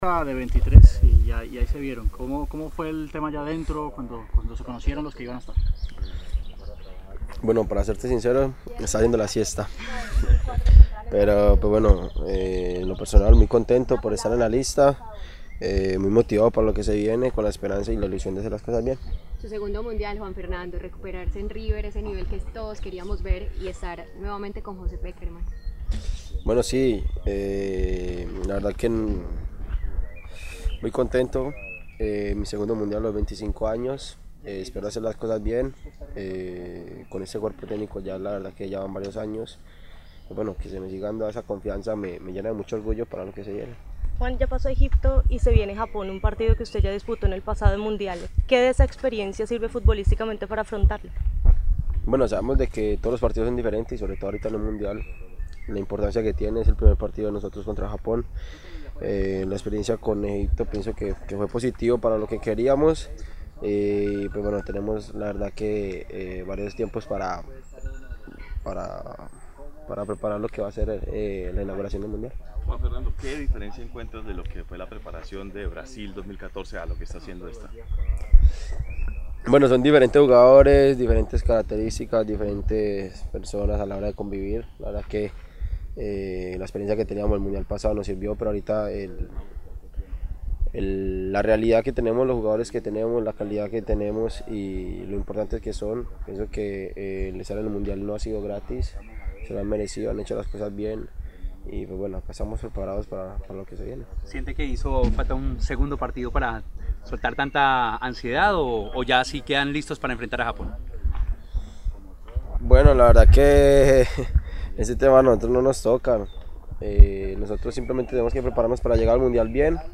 Antes del entrenamiento de la tarde, los jugadores Yerry Mina y Juan Fernando Quintero atendieron a la prensa para hablar del presente de la Selección Colombia, y la lista definitiva entregada por el entrenador.